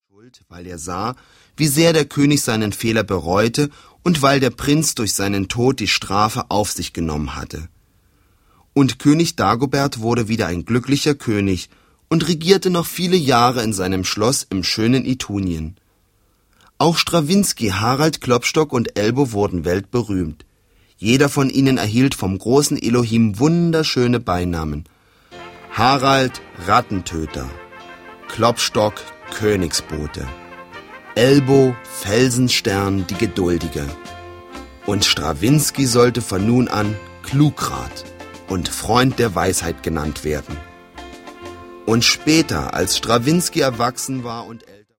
• Sachgebiet: Hörspiele